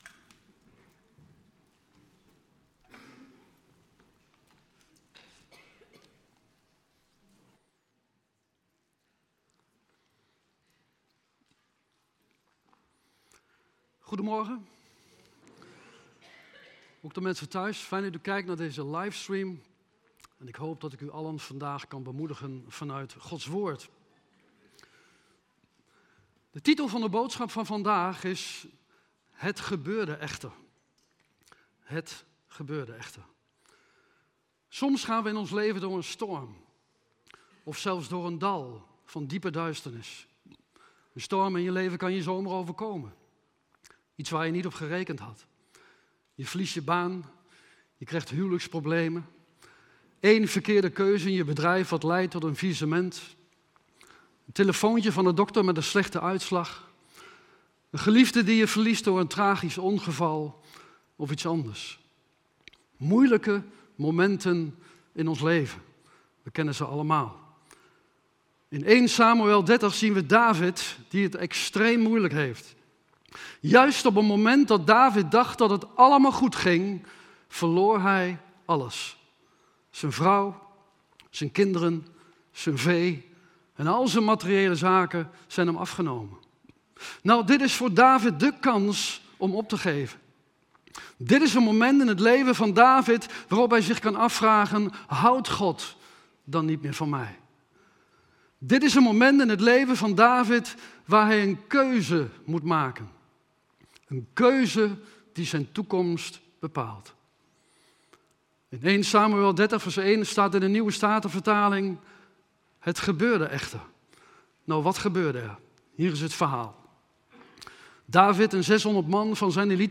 Het thema van de preek is: "HET GEBEURDE ECHTER".